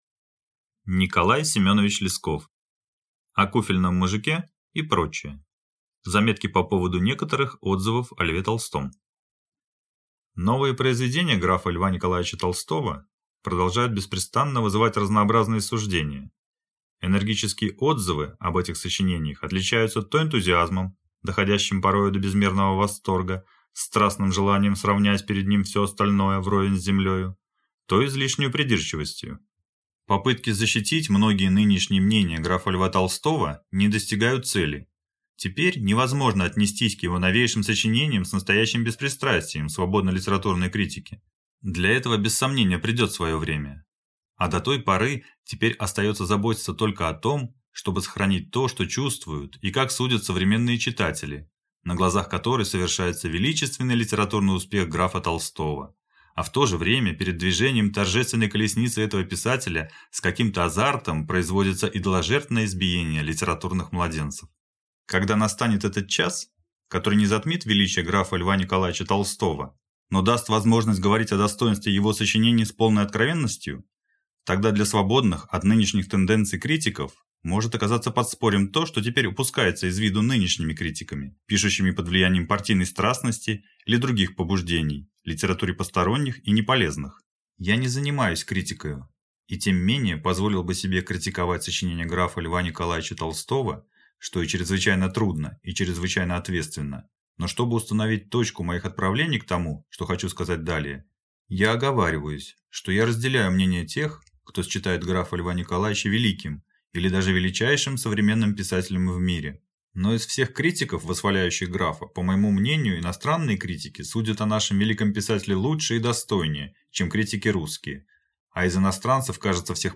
Аудиокнига О куфельном мужике и проч.
Aудиокнига О куфельном мужике и проч.